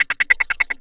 41 GIT01FX-L.wav